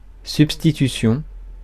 Ääntäminen
IPA: [syp.sti.ty.sjɔ̃]